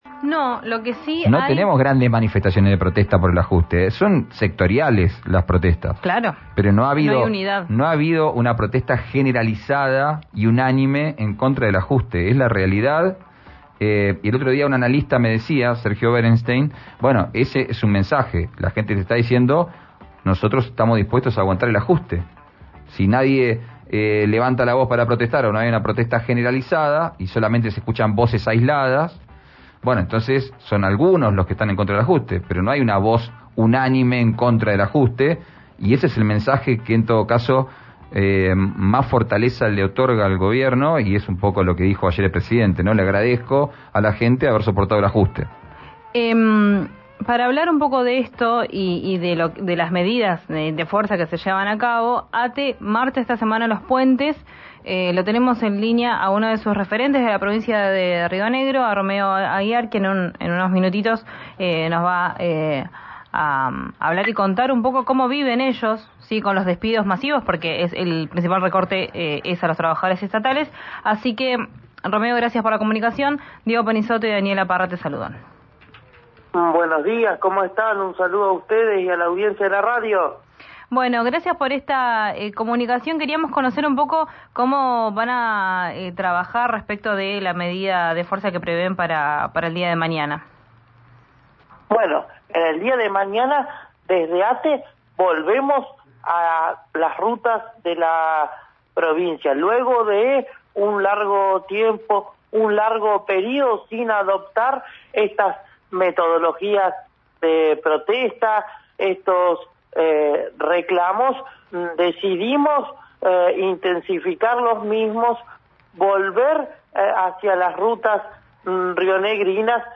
en diálogo con Vos al aire